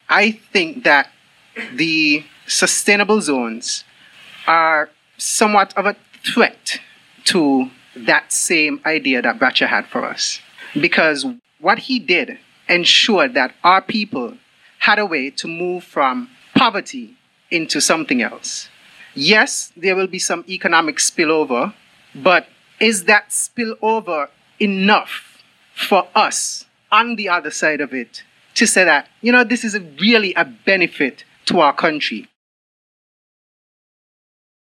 Prime Minister the Hon. Dr. Terrance Drew and Attorney General the Hon. Garth Wilkin, on Thursday evening October 16th, held a Town Hall Meeting with the people of Saint Kitts, to share information and insights on the transformative Special Sustainability Zones Authorization Act or (SSZ).
One person from the audience had this question:
SSZ-Townhall-1.mp3